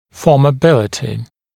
[fɔːmə’bɪlətɪ][фо:мэ’билэти]пластичность (о дуге)